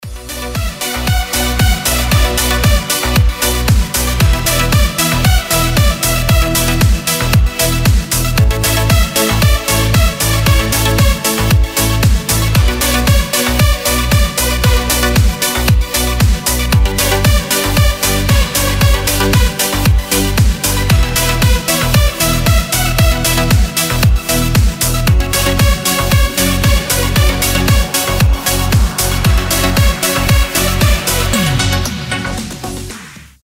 • Качество: 320, Stereo
громкие
красивые
без слов